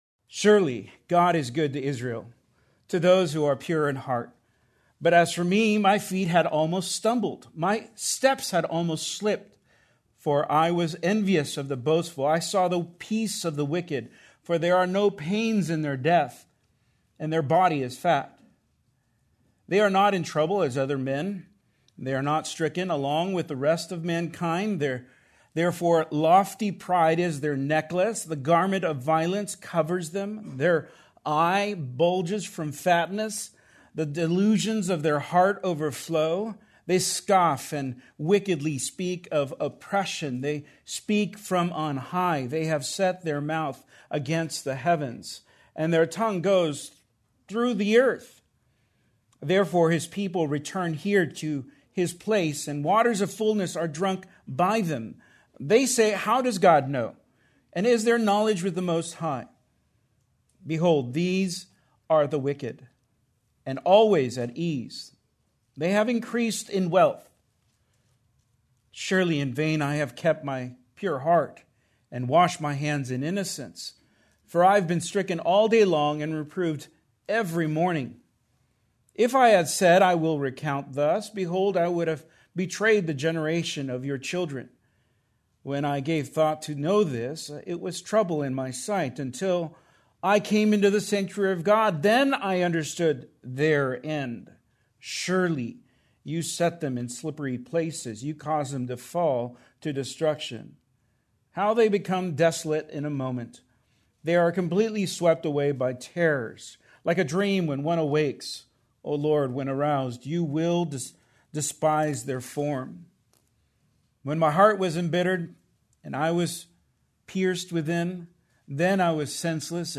Due to technical difficulties this sermon is incomplete.